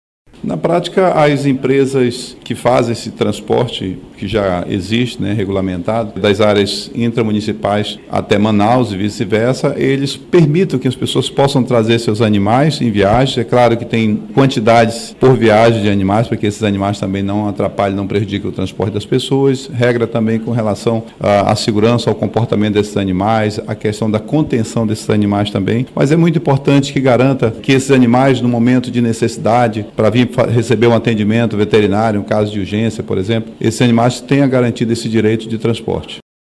O parlamentar explica como o projeto deve funcionar na prática.